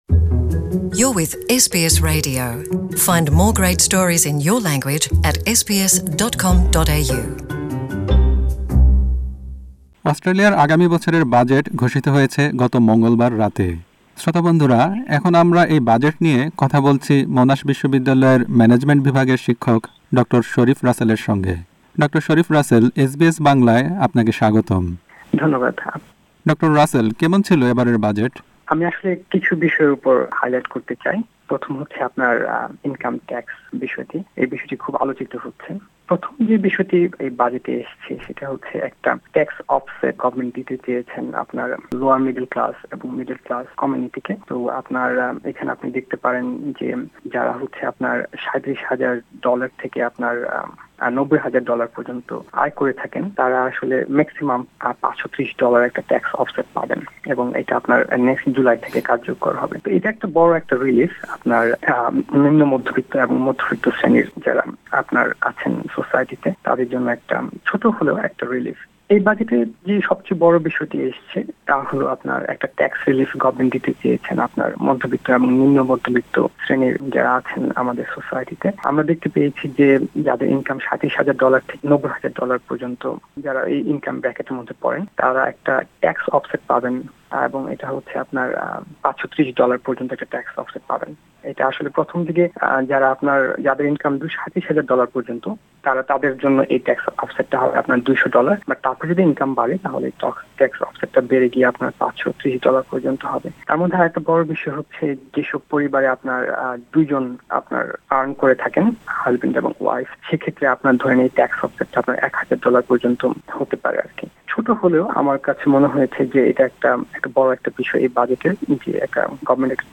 Budget 2018: Interview